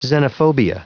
Prononciation du mot : xenophobia